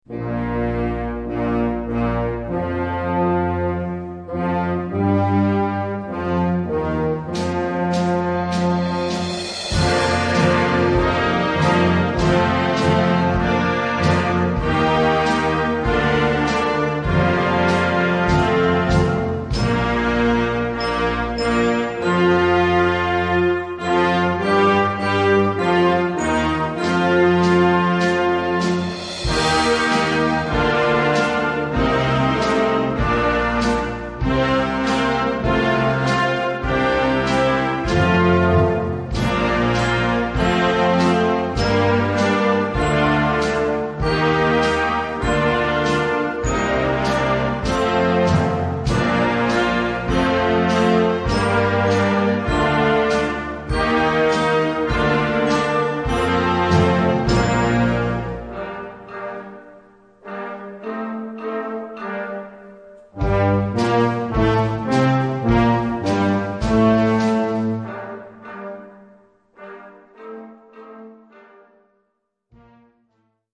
Gattung: Fanfare
Besetzung: Blasorchester